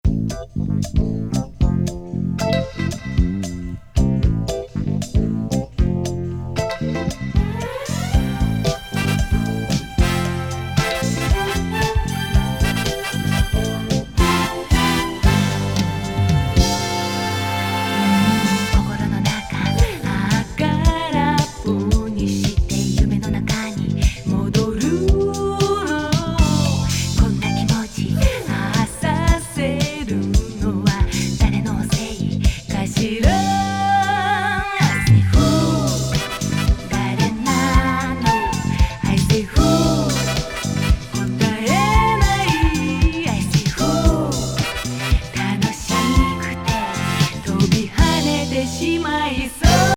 スムース・シティ・ディスコ
メロウ・ソウル歌謡